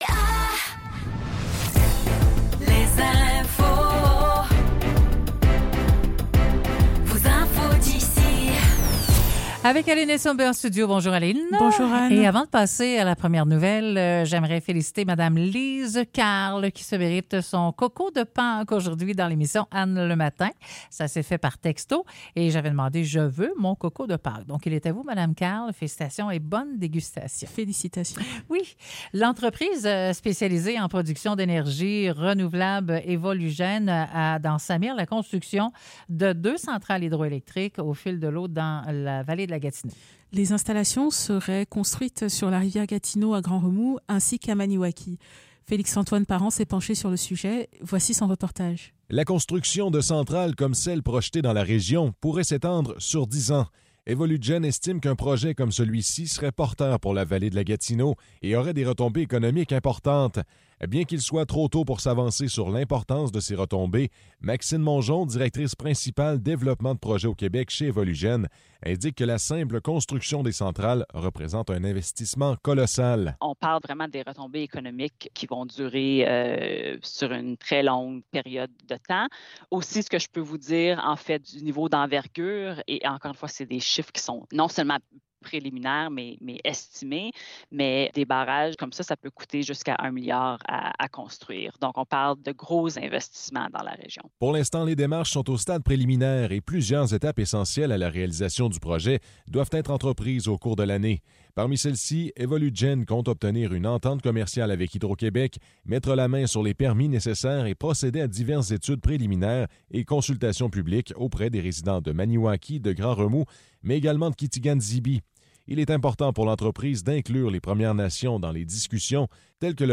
Nouvelles locales - 18 mars 2024 - 10 h